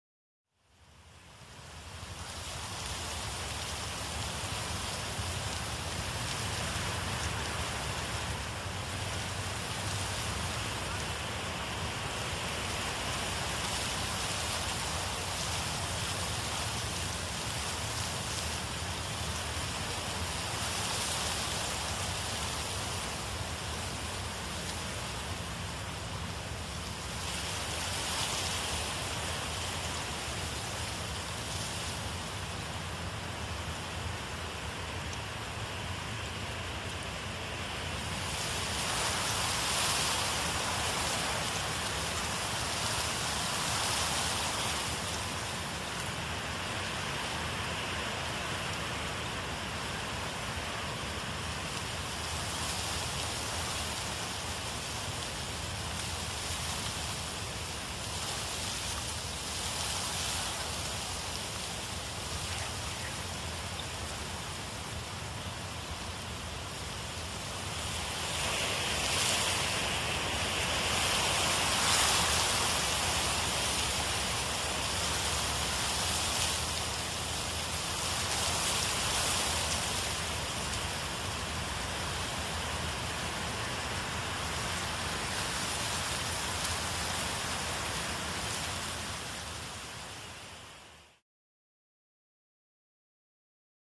جلوه های صوتی
دانلود صدای باد 4 از ساعد نیوز با لینک مستقیم و کیفیت بالا